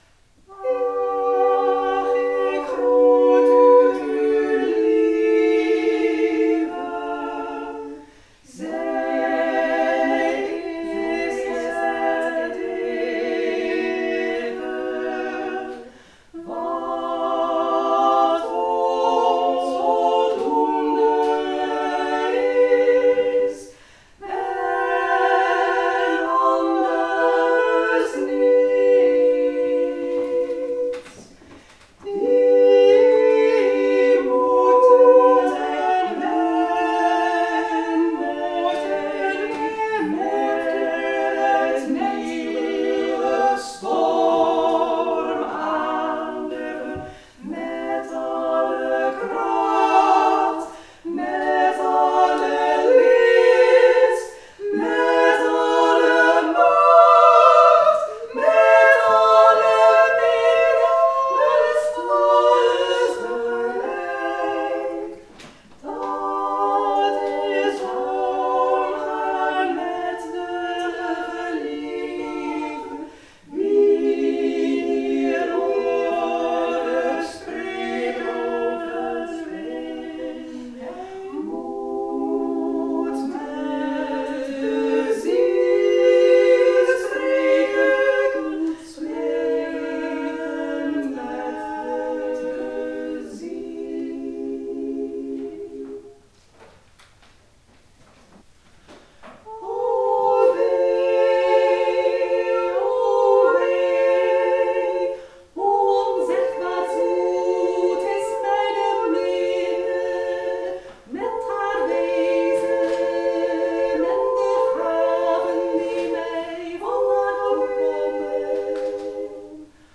Vierluik voor vrouwenkoor of drie solisten op teksten van Hadewijch (dertiende eeuw)
Het werd een vierluik voor drie vrouwenstemmen (of driestemmig vrouwenkoor).